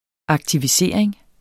aktivisering substantiv, fælleskøn Bøjning -en Udtale [ ɑgtiviˈseˀɐ̯eŋ ] Betydninger 1. det at aktivisere Rønne Byforening blev stiftet den 2. december 1959.